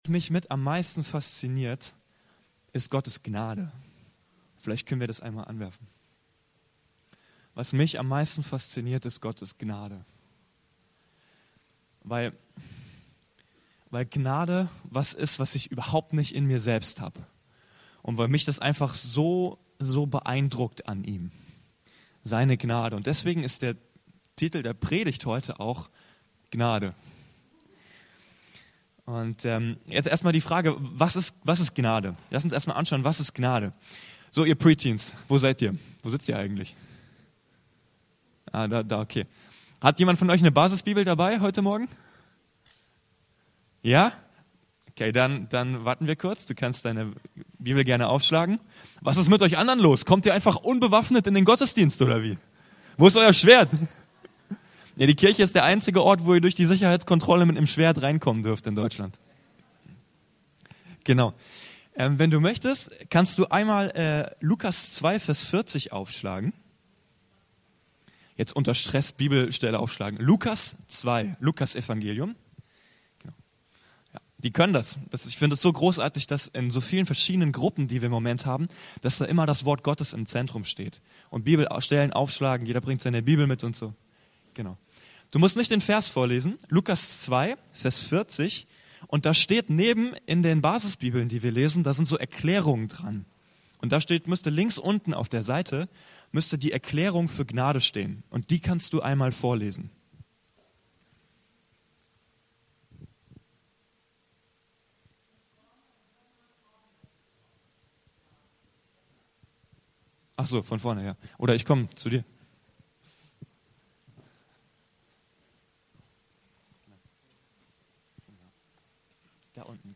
Gnade ~ Anskar-Kirche Hamburg- Predigten Podcast